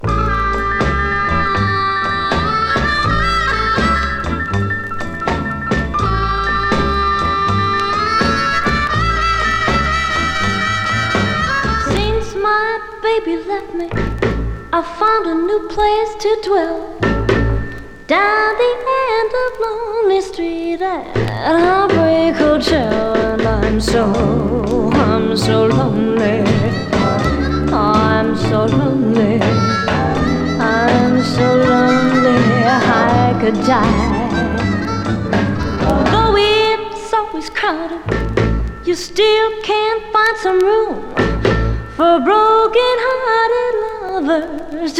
Rock, Pop, Vocal　Australia　12inchレコード　33rpm　Mono